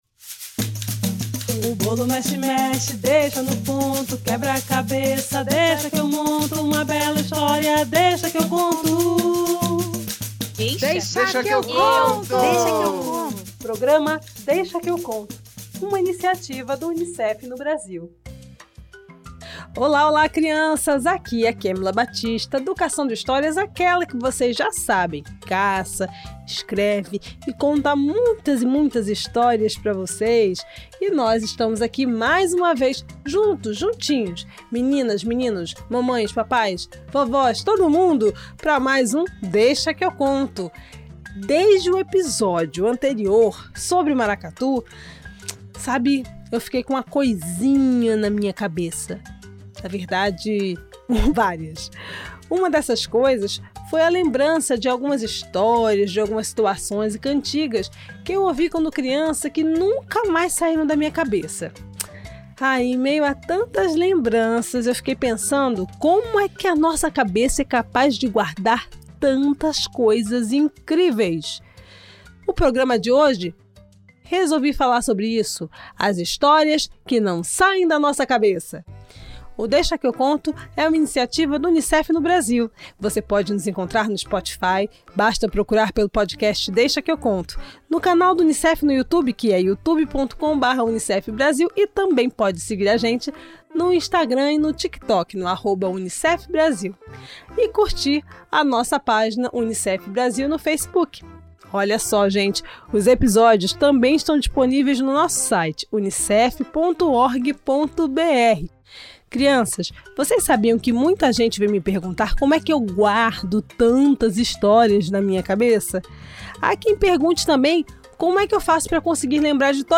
Neste programa você vai encontrar BNCC História Curiosidade Iorubá Entrevista Música Cabecilé